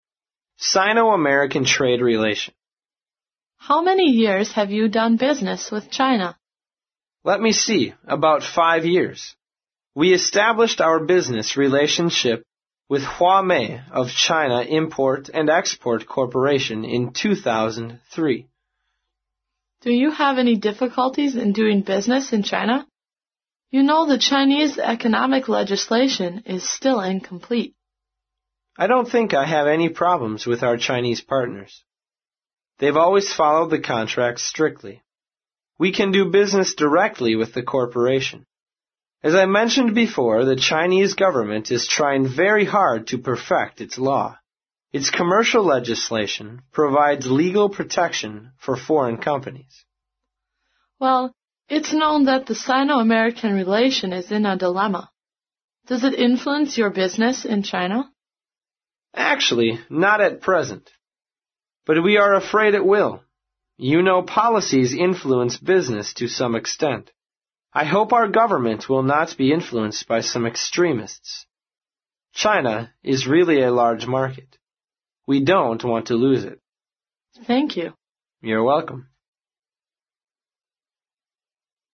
在线英语听力室外贸英语话题王 第48期:中美贸易关系的听力文件下载,《外贸英语话题王》通过经典的英语口语对话内容，学习外贸英语知识，积累外贸英语词汇，潜移默化中培养英语语感。